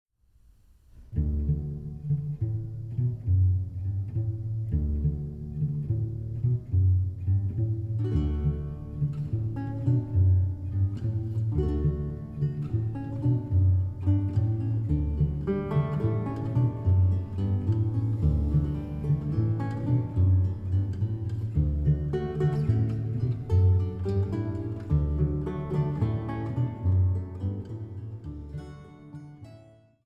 durch Flöten, Hackbrett und diverse Continuo-Instrumente